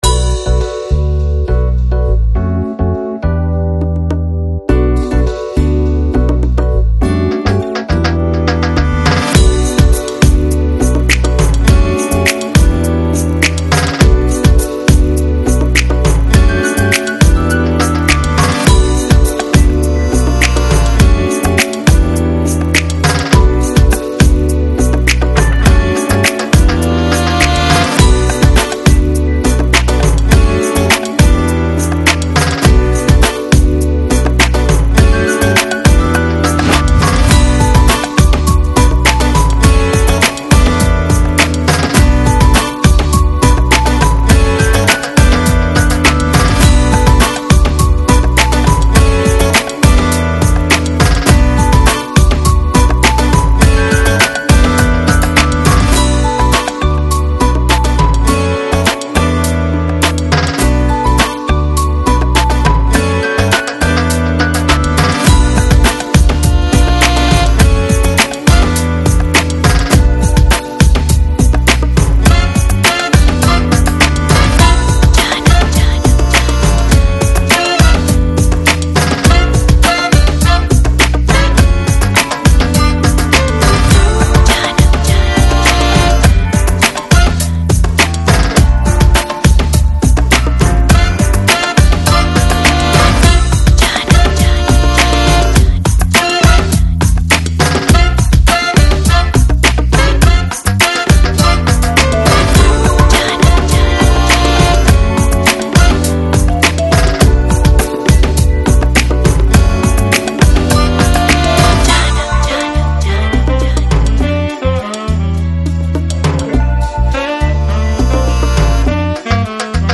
Жанр: Electronic, Chill Out, Lounge, Downtempo